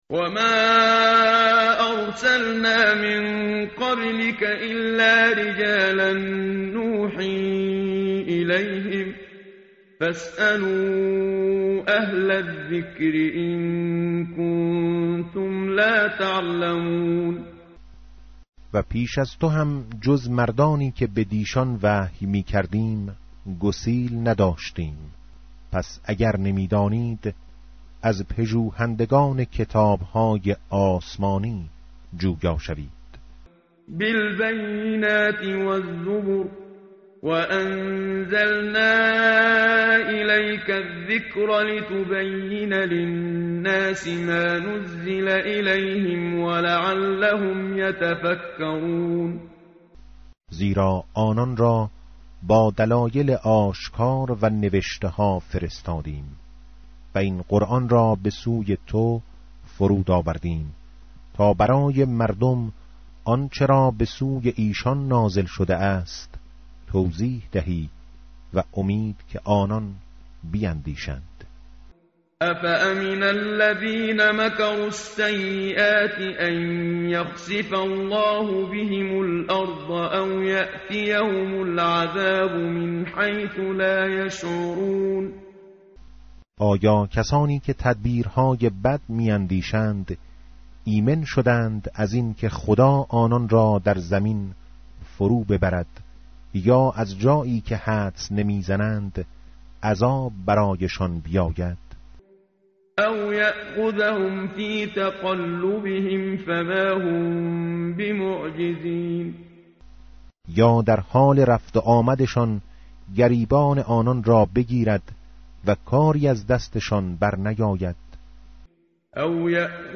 متن قرآن همراه باتلاوت قرآن و ترجمه
tartil_menshavi va tarjome_Page_272.mp3